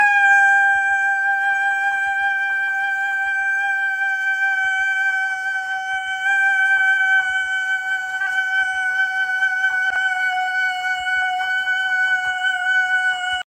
An egg scream a day sound effects free download